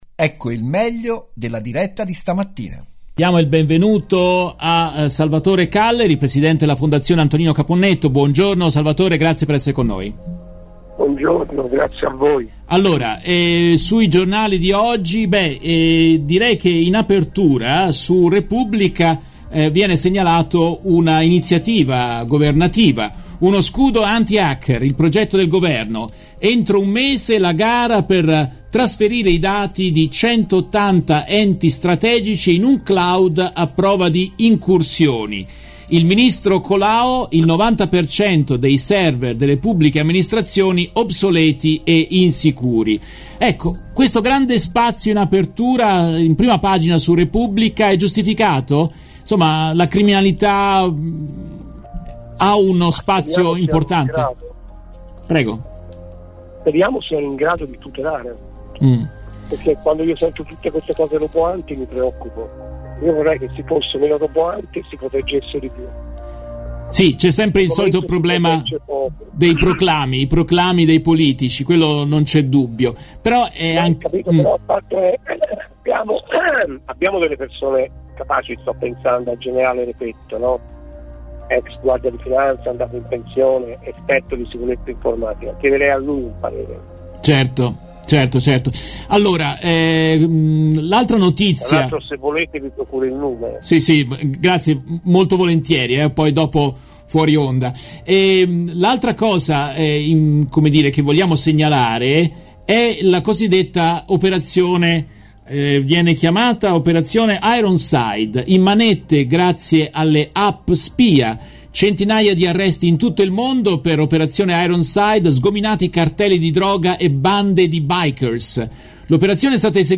In questa intervista tratta dalla diretta RVS del 8 giugno 2021